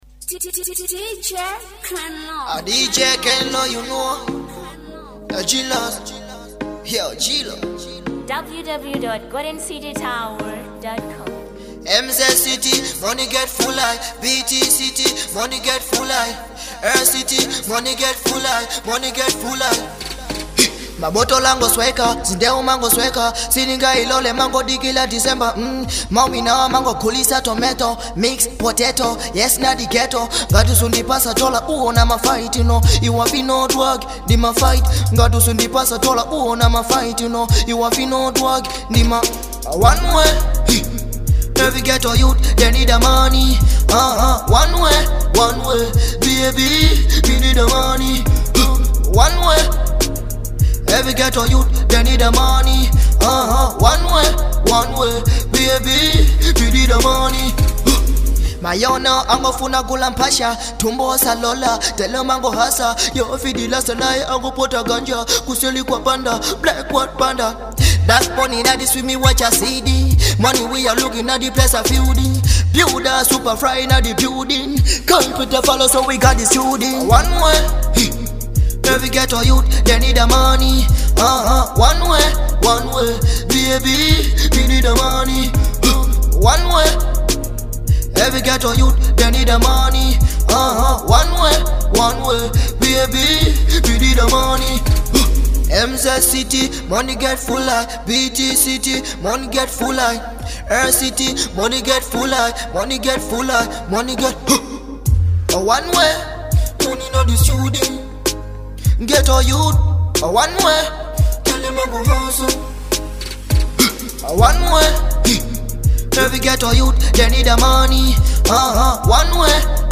2. Dancehall